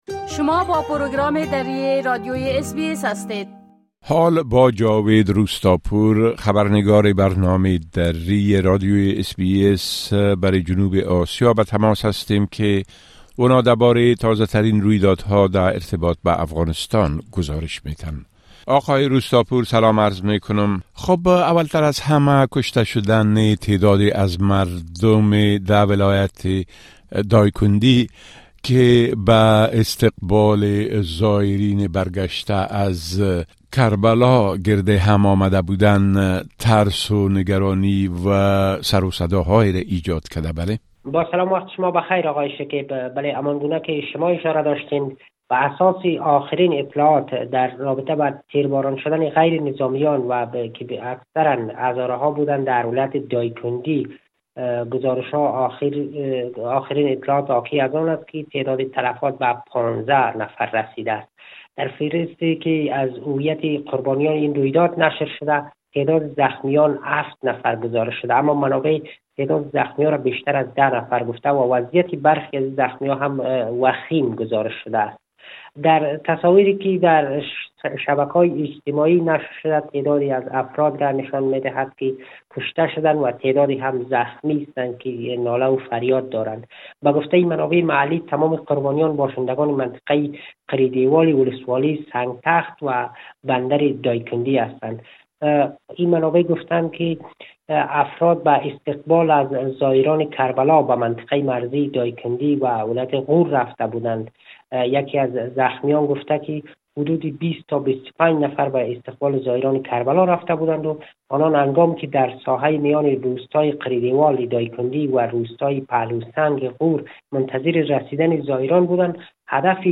خبرنگار ما برای جنوب آسیا: ده ها نفر در يك حملۀ داعش در ولايت دايكندى به قتل رسيده اند
گزارش كامل خبرنگار ما، به شمول اوضاع امنيتى و تحولات مهم ديگر در افغانستان را در اين‌جا شنيده مى توانيد.